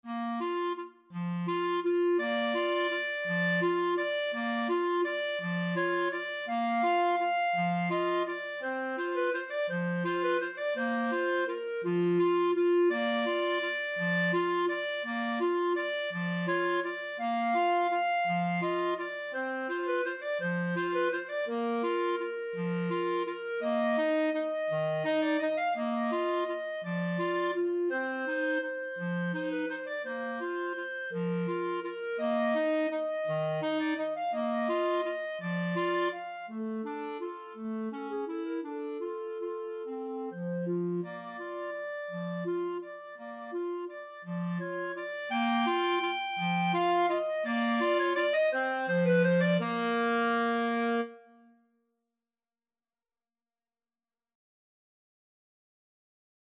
3/4 (View more 3/4 Music)
Tempo di valse =168
Clarinet Duet  (View more Easy Clarinet Duet Music)
Classical (View more Classical Clarinet Duet Music)